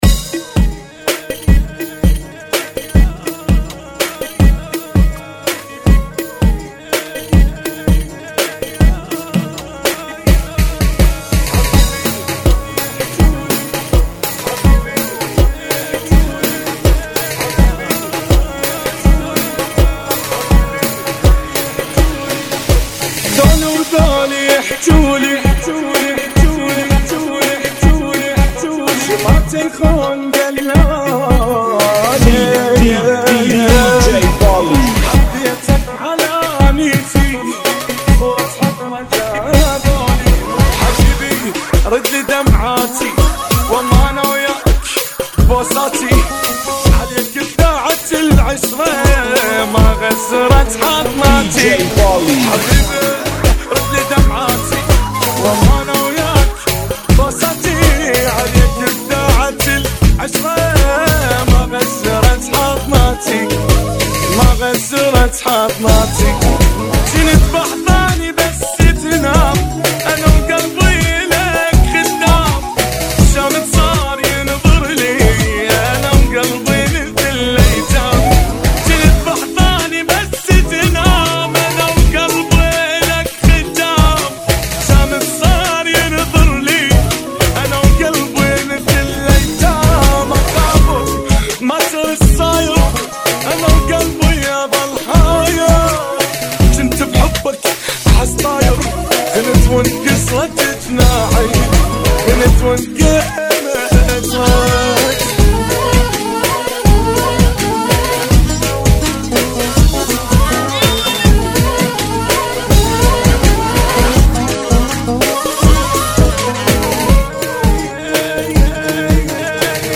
82 BPM